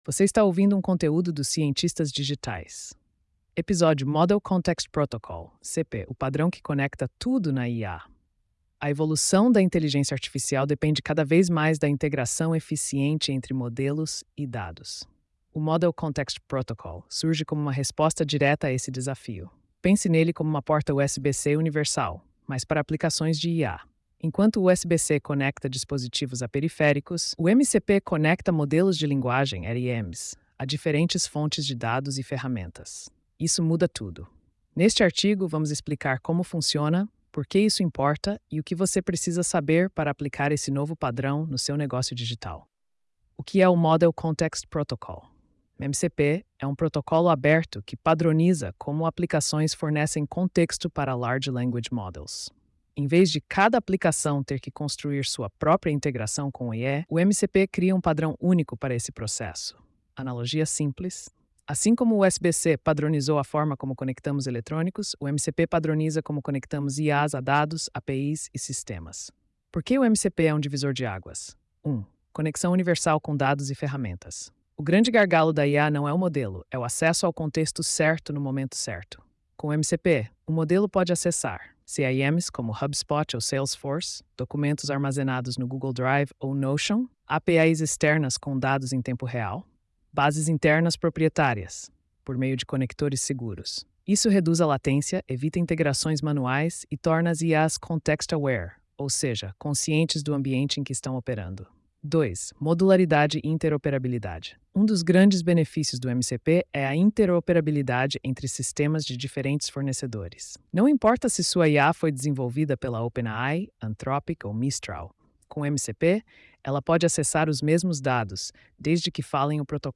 post-3050-tts.mp3